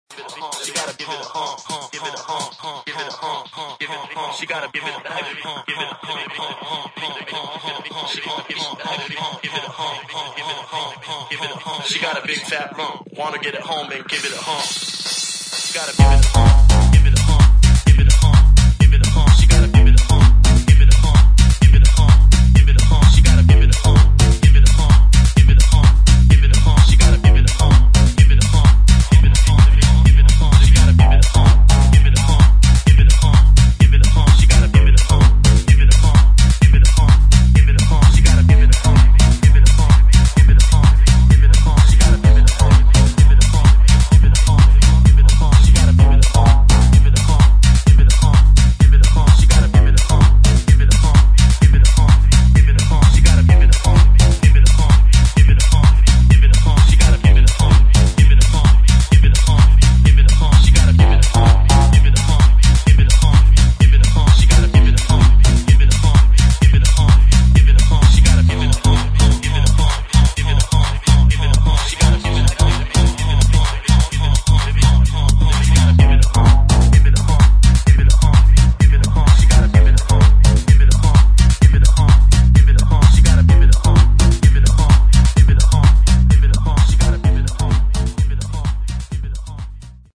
[ HOUSE / TECHNO ]